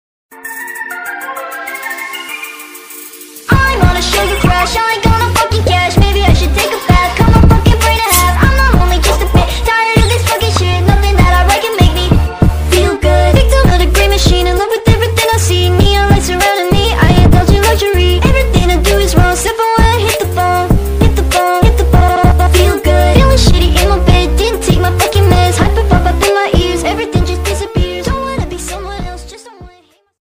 Category: BGM